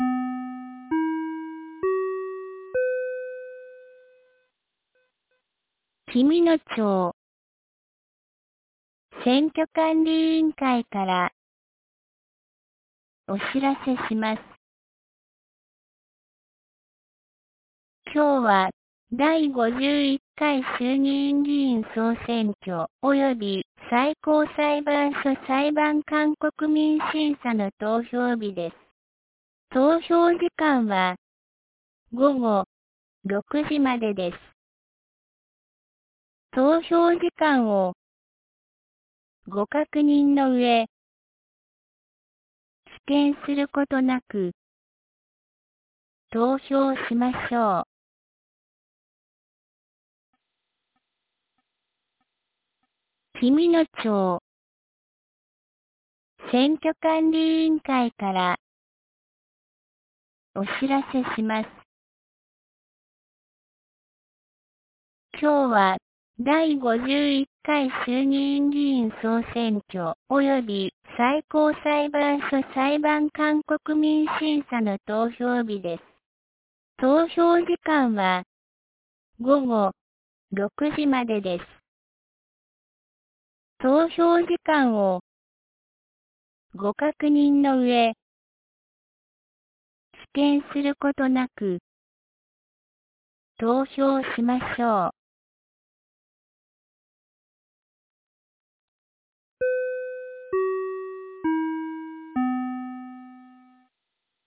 2026年02月08日 12時31分に、紀美野町より全地区へ放送がありました。